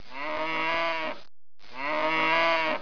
دانلود صدای حیوانات جنگلی 54 از ساعد نیوز با لینک مستقیم و کیفیت بالا
جلوه های صوتی